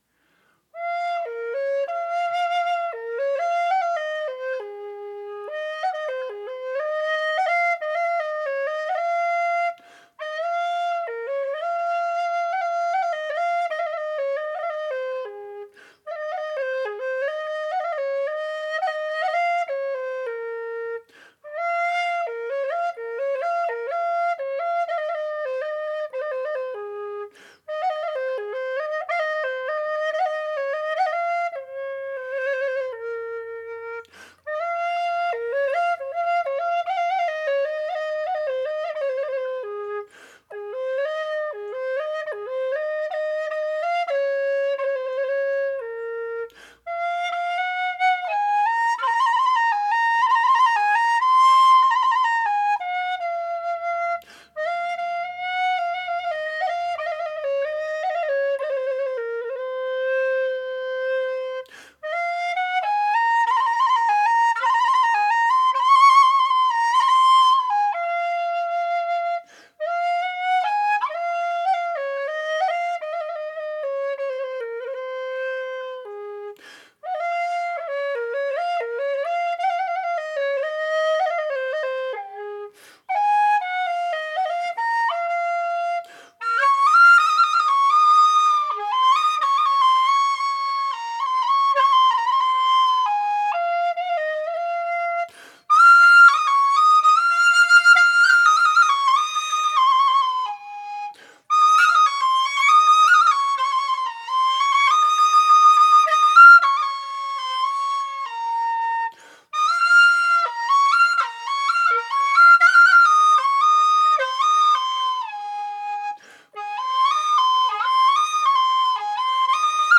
Ab/G# Whistles
made out of thin-walled aluminium tubing with 15.7mm bore
Ab-impro-3.mp3